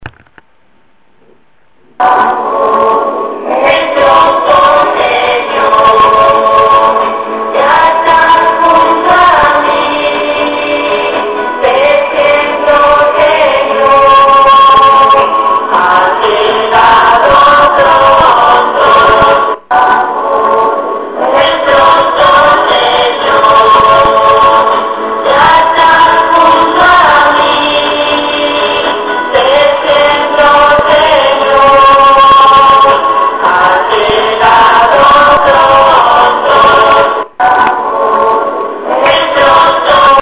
LAS NOVENAS Y ACTOS RELIGIOSOS ESTUVIERON SIEMPRE ACOMPAÑADOS POR LAS VOCES DULCES Y ALEGRES DE LA NIÑAS DEL CORO JUVENIL O POR LAS VOCES MELODIOSAS Y HONDAS DEL CORO DE MAYORES
ASÍ CANTA EL CORO JUVENIL
valle2008COROJUVENIL.MP3